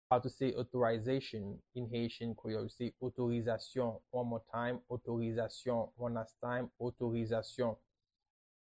“Authorization” in Haitian Creole – “Otorizasyon” pronunciation by a native Haitian teacher
“Otorizasyon” Pronunciation in Haitian Creole by a native Haitian can be heard in the audio here or in the video below:
How-to-say-Authorization-in-Haitian-Creole-–-Otorizasyon-pronunciation-by-a-native-Haitian-teacher.mp3